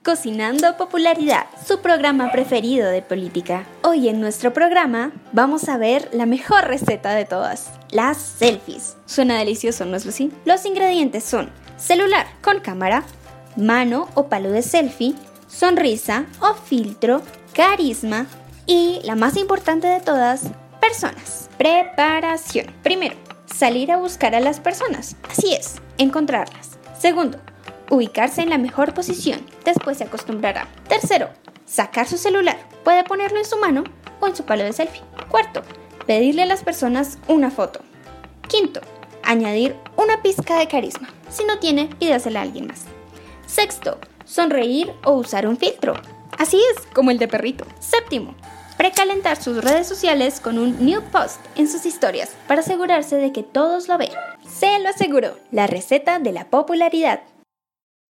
Contó con la participación intergeneracional de usuarios de la mencionada biblioteca. ¡No te pierdas la receta de la popularidad!...Pasos para obtener las mejores selfies.
Producción radial, Fotografía